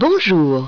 A voz da guia L addition s il vous plaitA conta por favor!
bonjour.wav